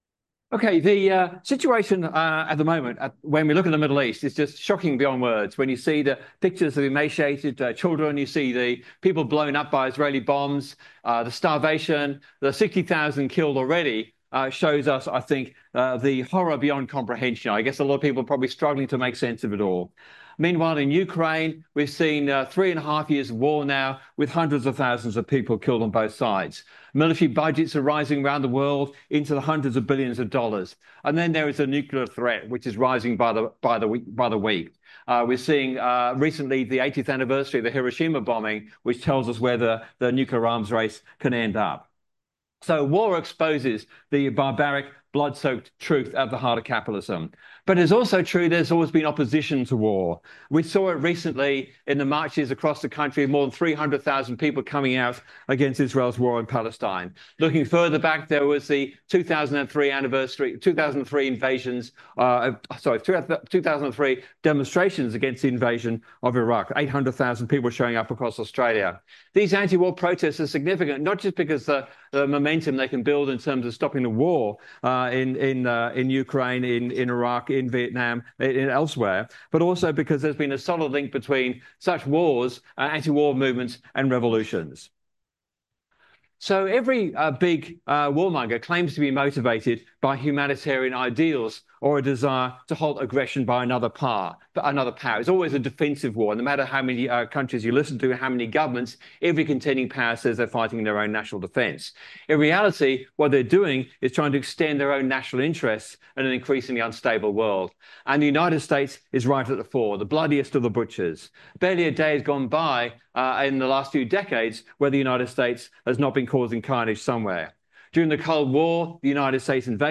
Socialism 2025 (Brisbane)